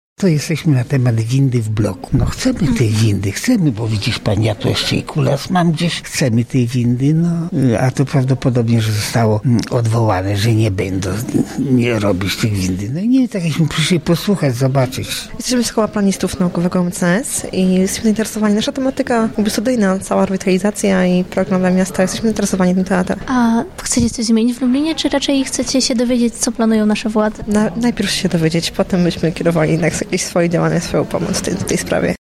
sonda